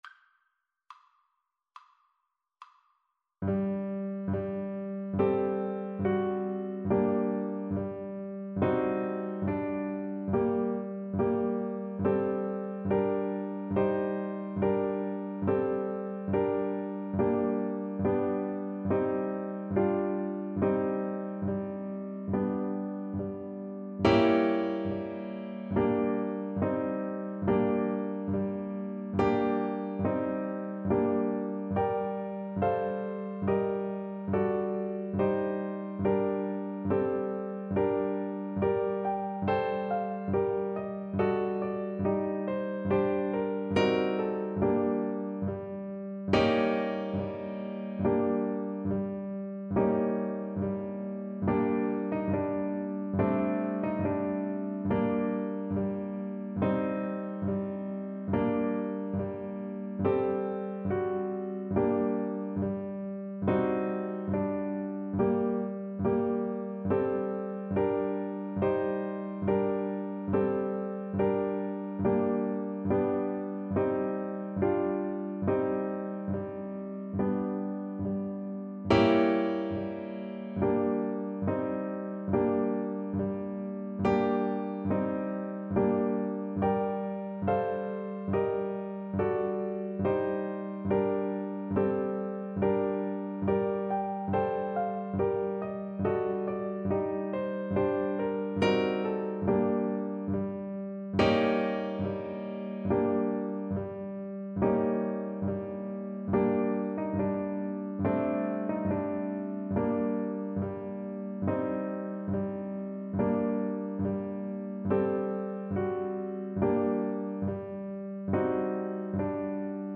Andante sostenuto =70
Classical (View more Classical Clarinet Music)